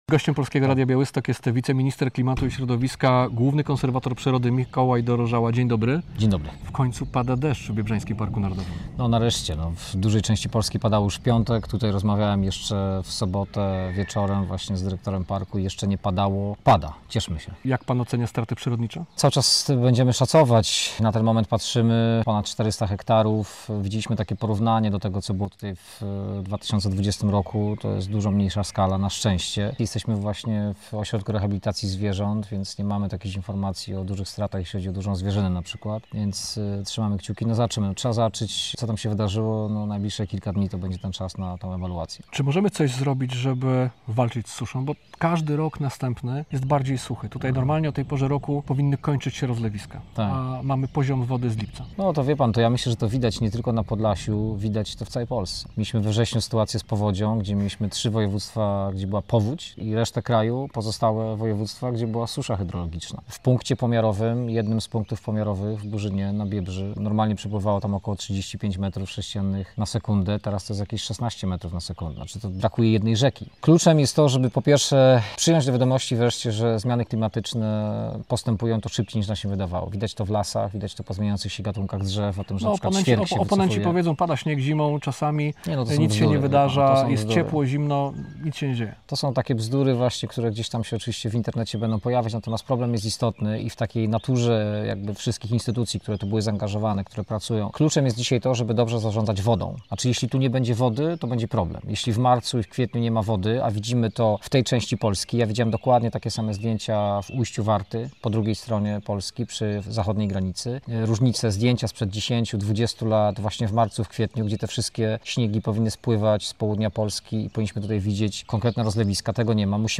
Radio Białystok | Gość | Mikołaj Dorożała [wideo] - główny konserwator przyrody